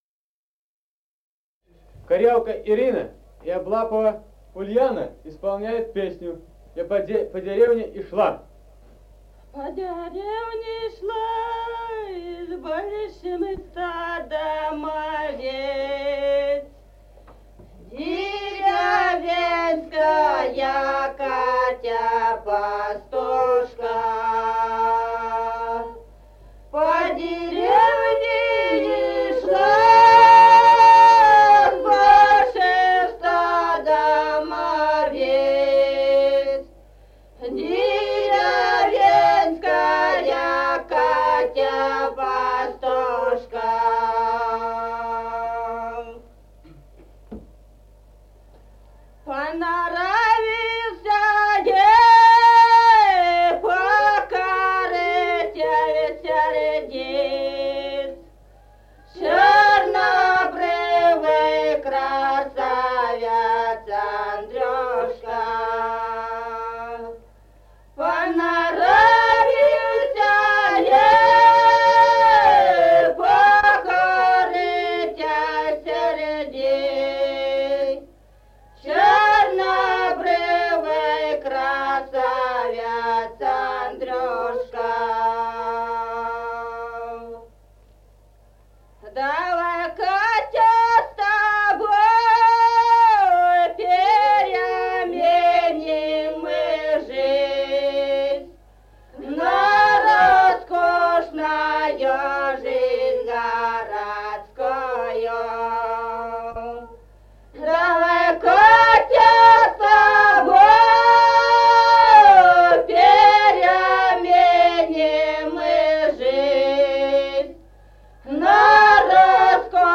Музыкальный фольклор села Мишковка «По деревне ишла», лирическая.